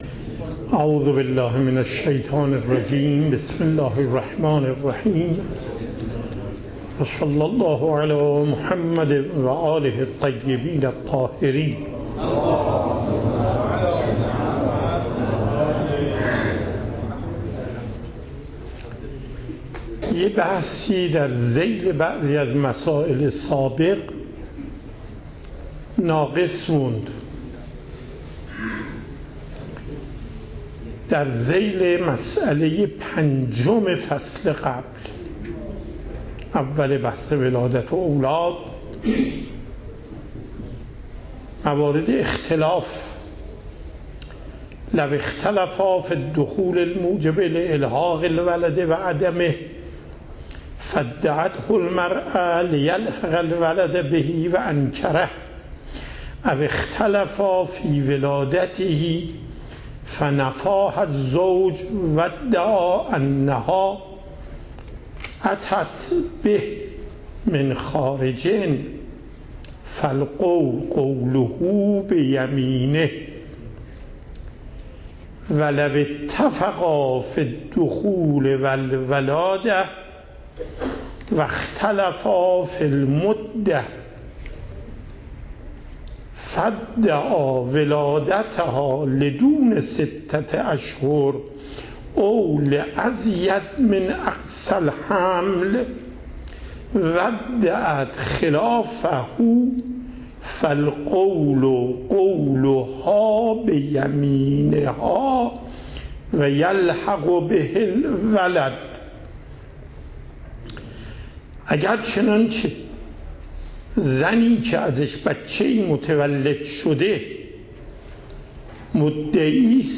صوت و تقریر درس پخش صوت درس: متن تقریر درس: ↓↓↓ موضوع: کتاب النکاح/الحاق ولد به زوج /شروط الحاق ولد به زوج اشاره ذیل مباحث سابق بحثی ناقص ماند.در ذیل ….